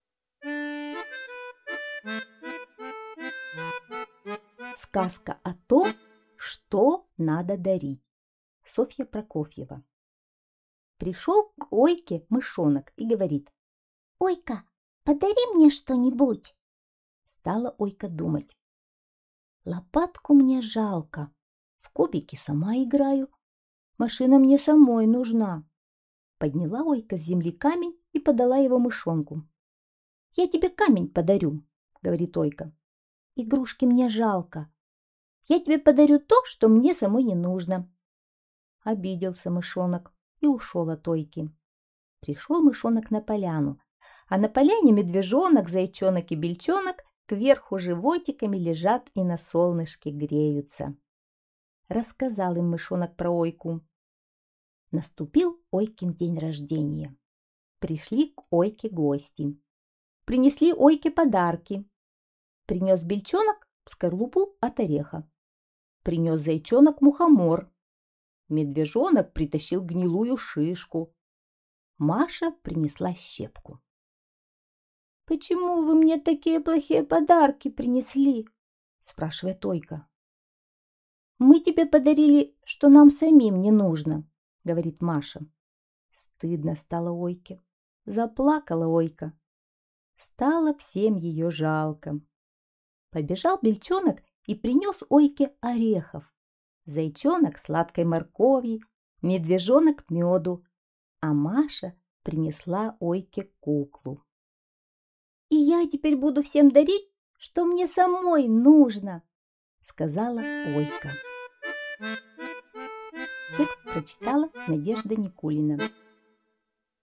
Сказка о том, что надо дарить - аудиосказка Прокофьевой С.Л. Пришёл к Ойке Мышонок и попросил что-нибудь подарить ему...